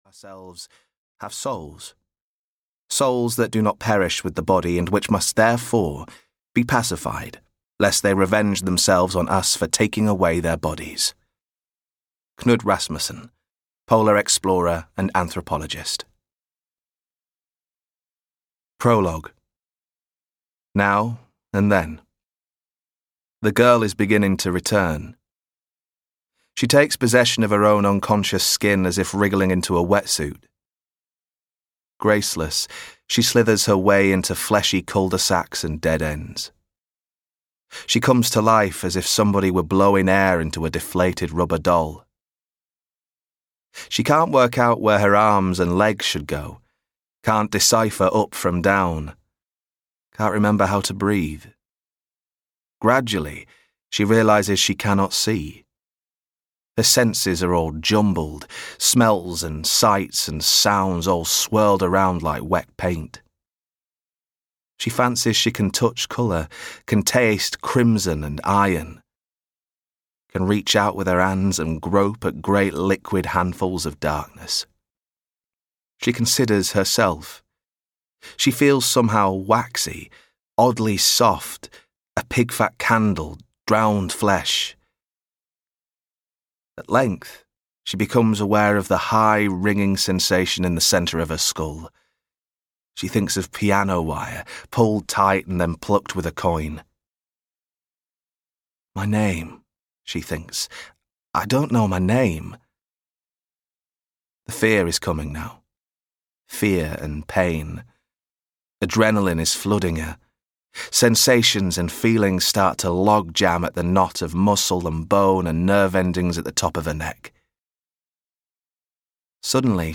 Into the Woods (EN) audiokniha
Ukázka z knihy